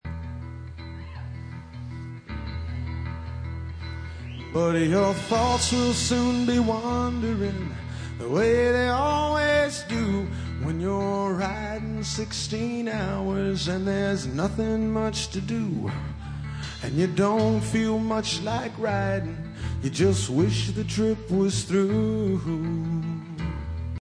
His singing is quiet, but still expressive.